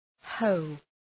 Προφορά
{həʋ}